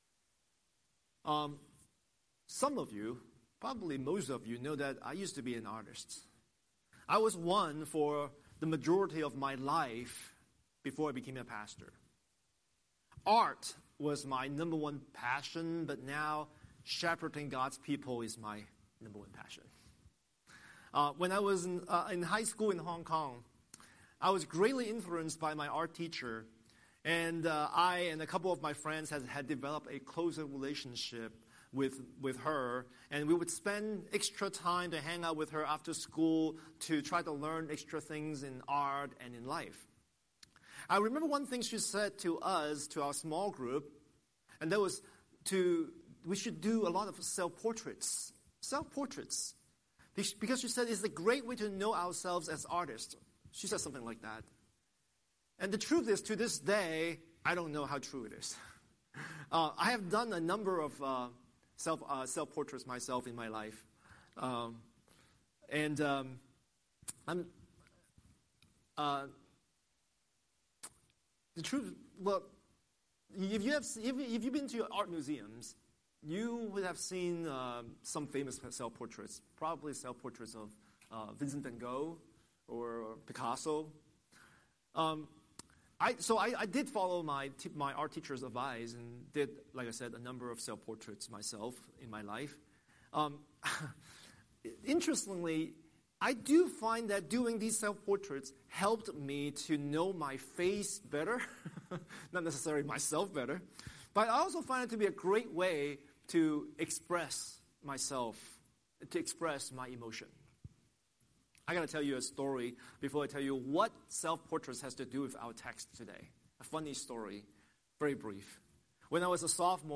Scripture: 1 Timothy 1:12–17 Series: Sunday Sermon